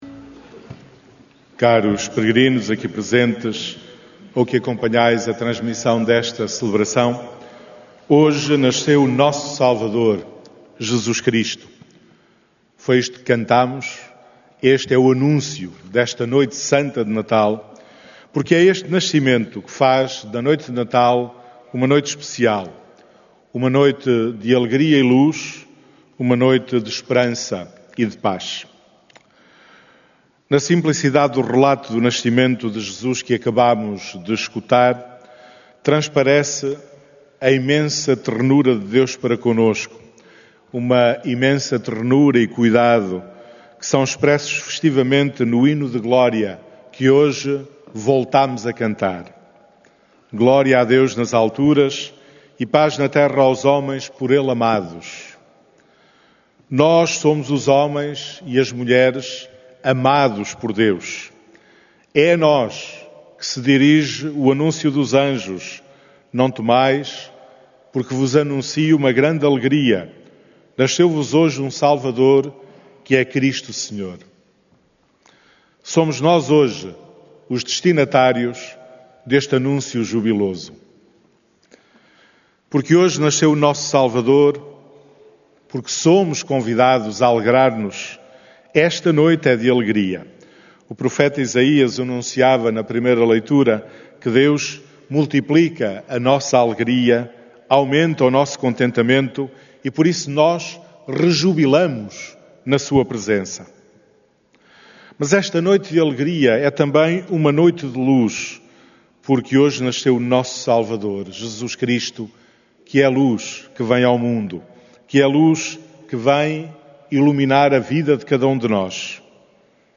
Áudio da homilia
missa da noite do natal